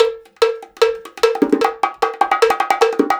150BONGO 6.wav